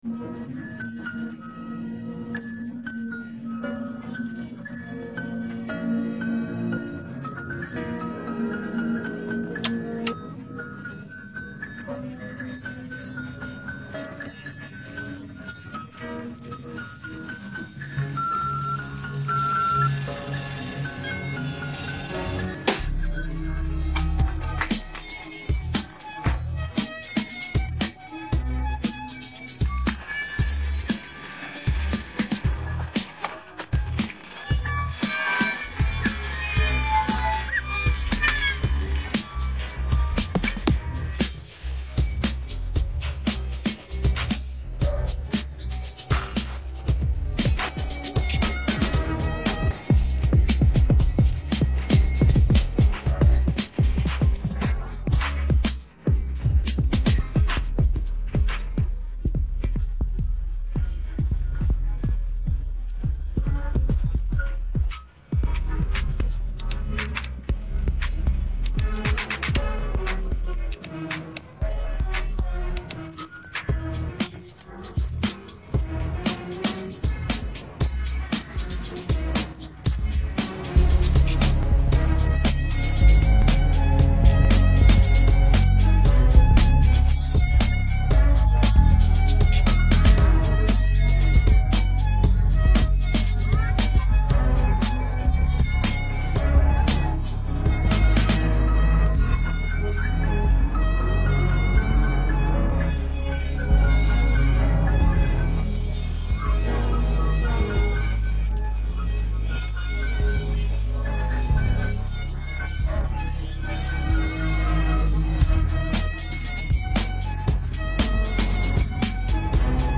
Фоновая музыка